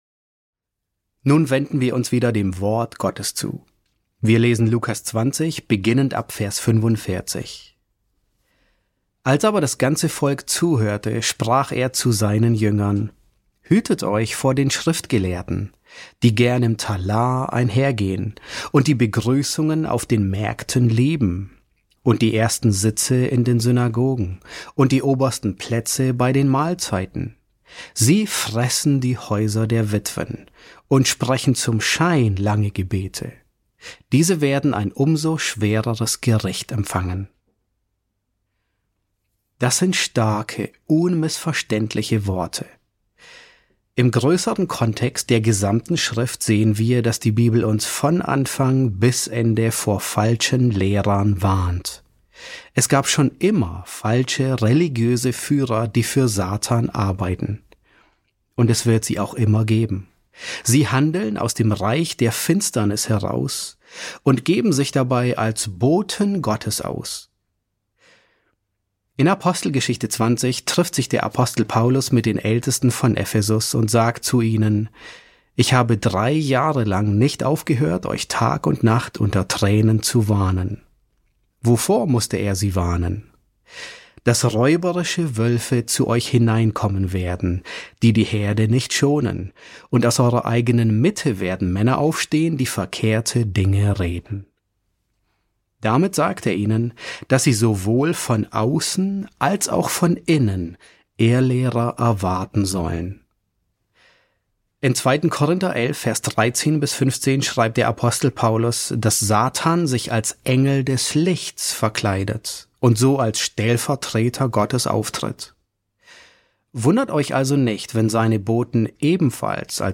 S7 F8 | Kein Dialog mit denen auf Irrwegen, sondern Konfrontation und Verurteilung ~ John MacArthur Predigten auf Deutsch Podcast